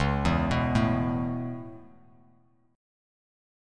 C#m7#5 chord